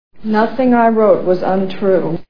Manhattan Movie Sound Bites